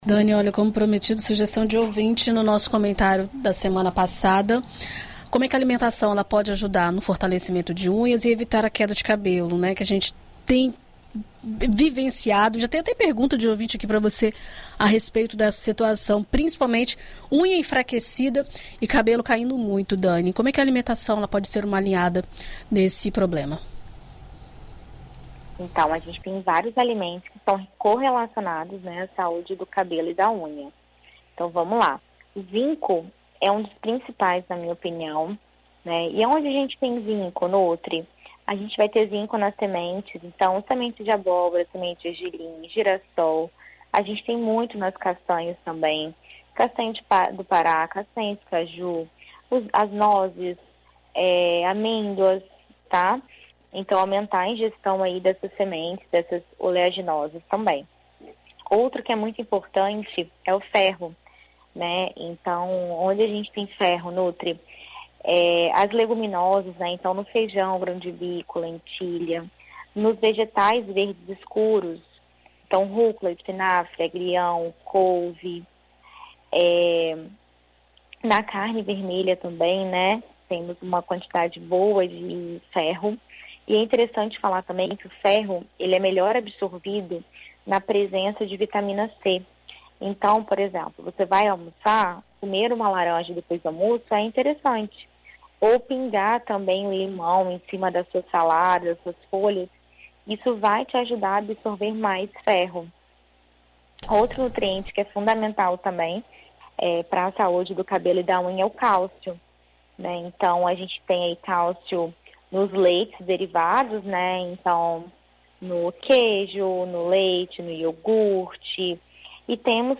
Na coluna Viver Bem desta quarta-feira (15), na BandNews FM Espírito Santo